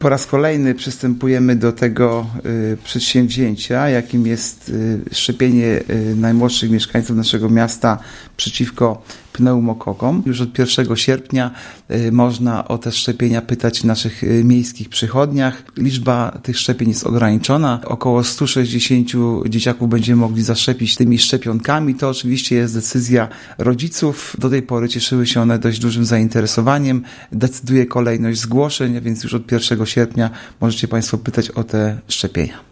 – Szczepieniami objęte zostaną dzieci urodzone w 2014 r. – mówi Artur Urbański, zastępca prezydenta Ełku.